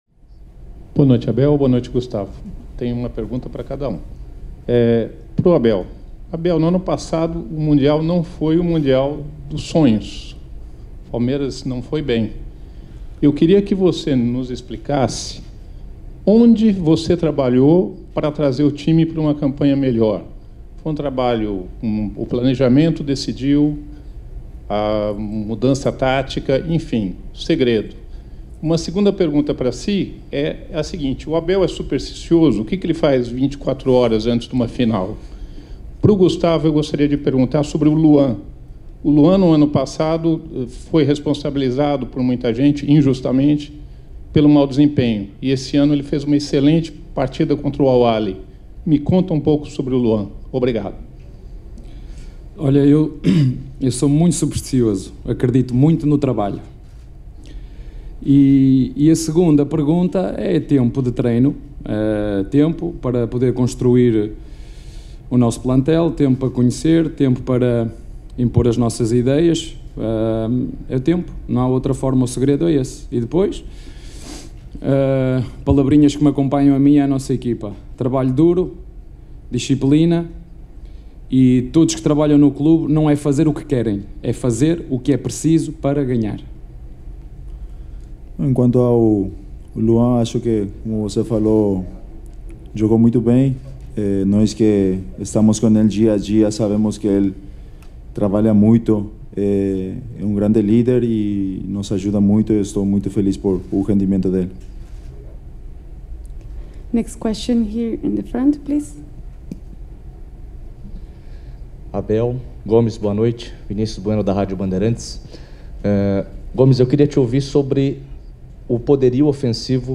COLETIVA-OFICIAL-_-ABEL-FERREIRA-E-GUSTAVO-GOMEZ-_-ABU-DHABI-1.mp3